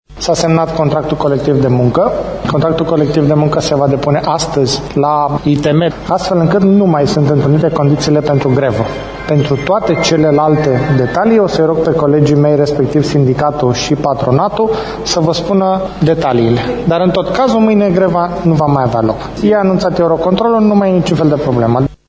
Anunțul a fost făcut, în această seară, de către ministrul Transporturilor, Iulian Matache: